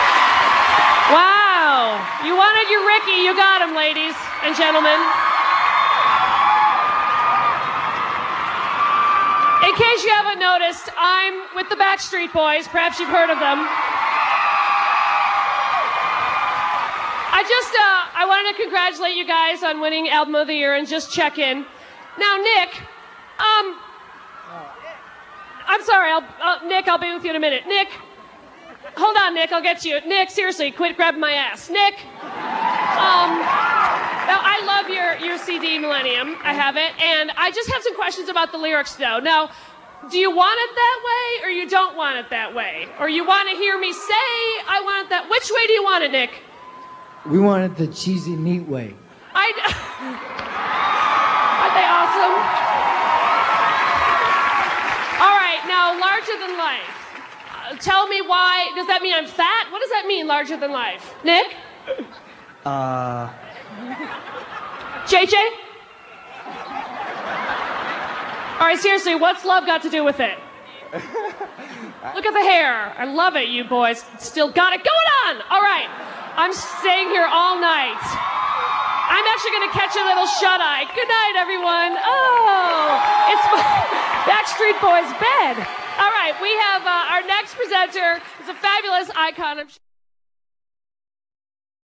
Billboard Interview 1:31 | 238 KB A hilarious interview at the Billboard Awards.